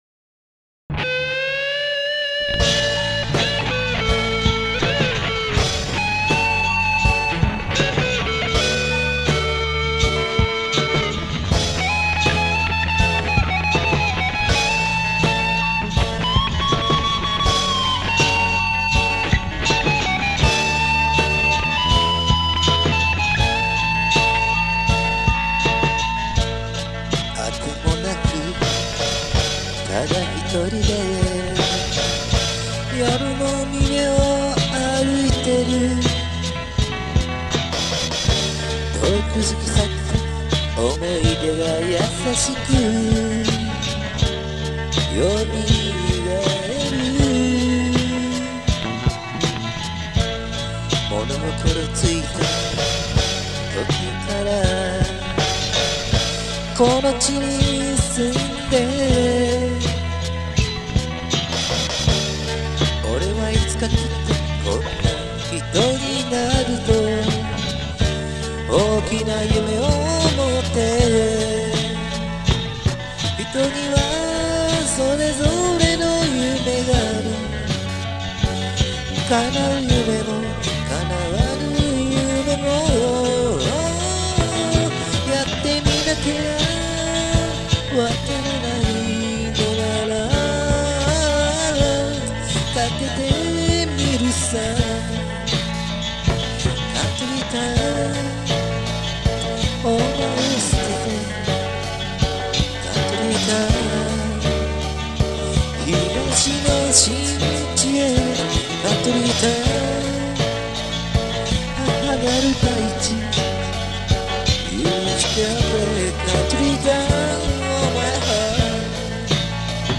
AG2  S.Yairi（Nashville）
ドラム  Pistoncollage
ですので演奏は殆ど１オクターブくらい下げて歌ってますわ(-"-;A ...アセアセ
＋デジタルディレイをかけて演奏してます。
エンディングの前半（歌と被る所）は、リアPUのディマジオトーンゾーンで弾いてます。
フロントとリアの出力の差はミキサーで調整してます。